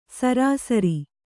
♪ sarāsari